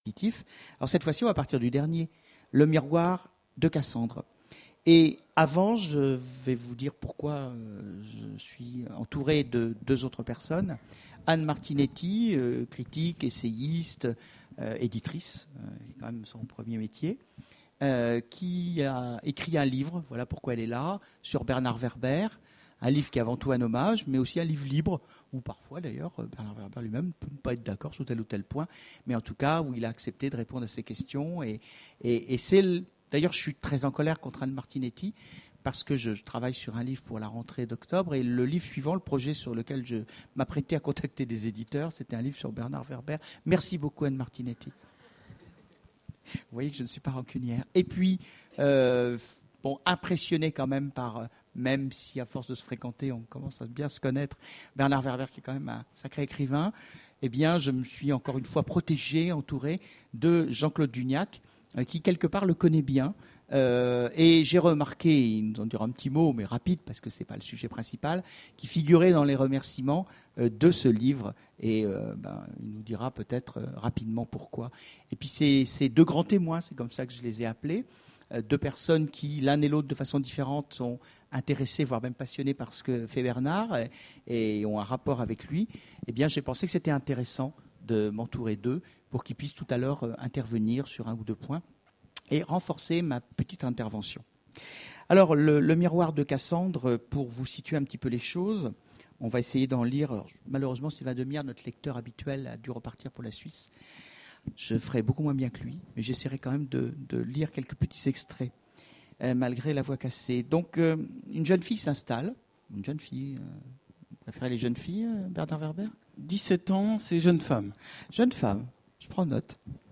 Conférence Imaginales 2010 : Bernard Werber Voici l'enregistrement de la conférence avec Bernard Werber aux Imaginales 2010. Malheureusement en raison d'un soucis technique nous n'avons que le début de l'entretien.